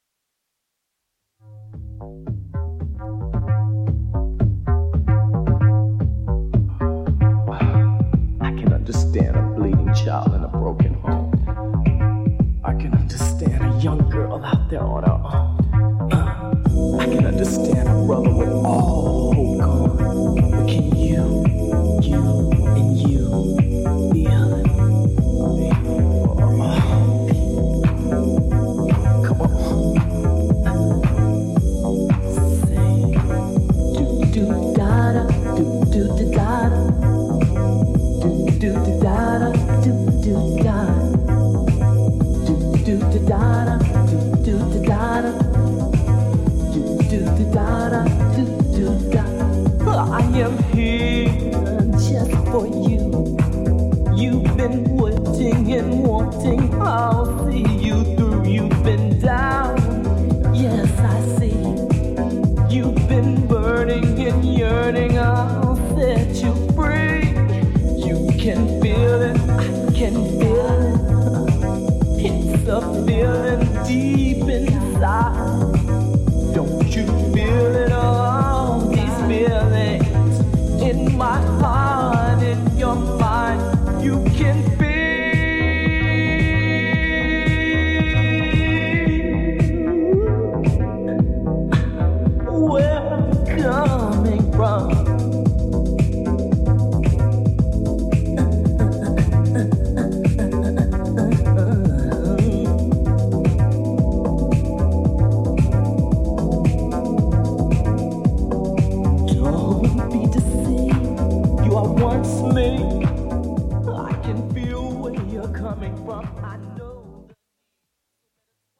ジャンル(スタイル) CLASSIC HOUSE / DEEP HOUSE / CHICAGO HOUSE